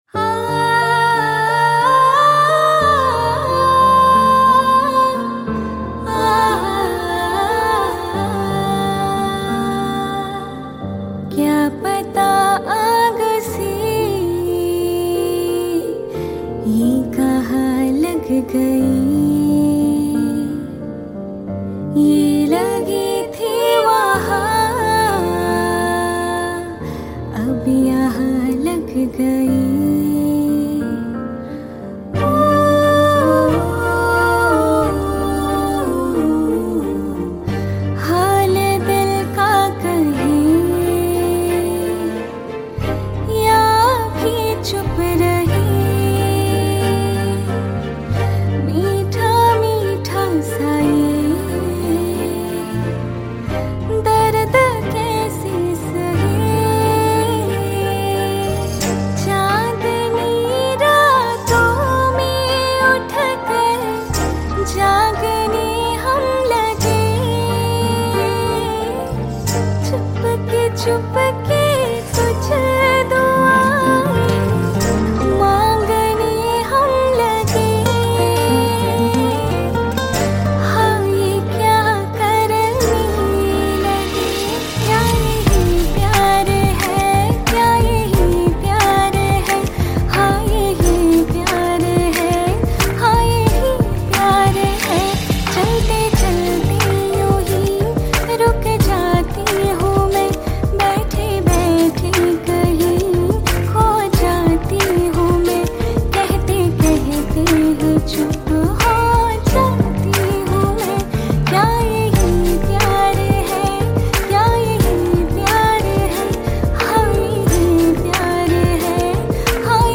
Bollywood Cover Songs